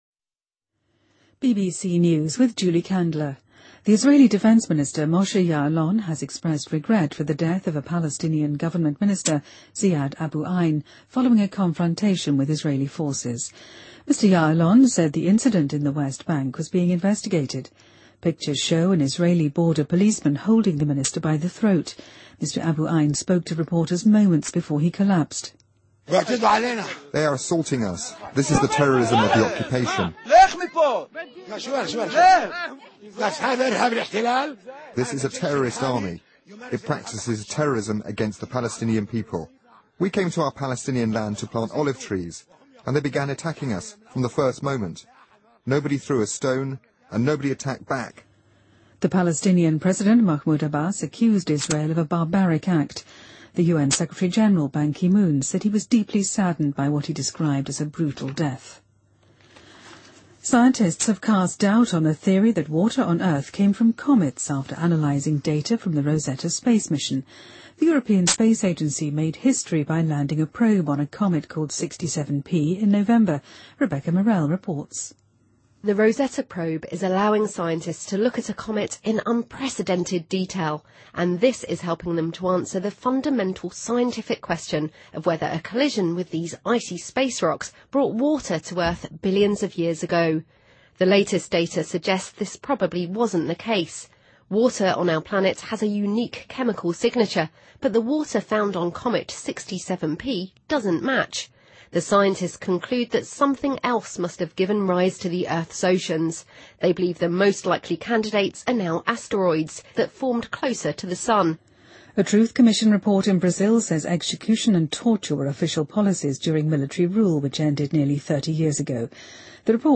BBC news,阿富汗总统贾尼谴责中央情报局使用野蛮审讯手段的行为